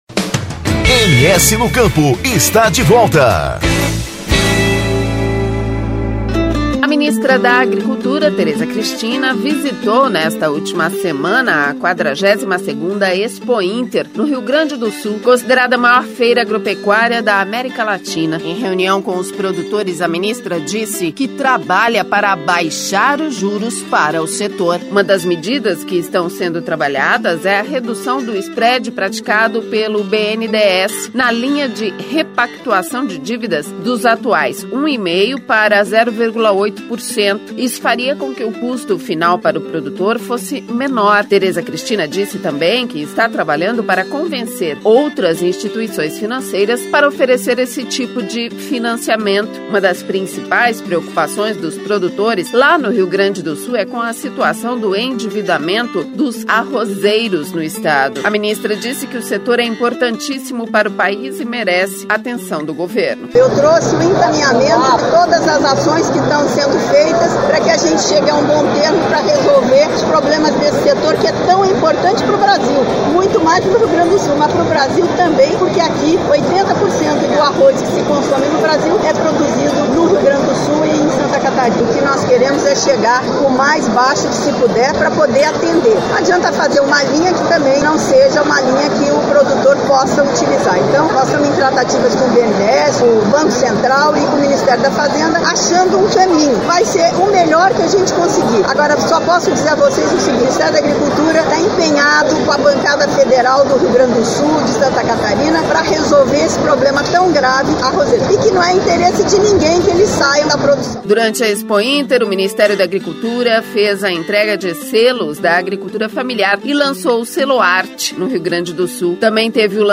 O programa ainda traz uma reportagem, sobre a negociação do Ministério da Agricultura com o BNDES para baixar os juros das dívidas agrícolas. A ministra Tereza Cristina participou da abertura da Expointer no Rio Grande do Sul e falou sobre o assunto.
Semanalmente distribuído para aproximadamente cem emissoras de rádio parceiras de frequência FM, com alcance nos 79 municípios, o MS no Campo também é disponibilizado para download no portal oficial do Governo de Mato Grosso do Sul, Portal MS.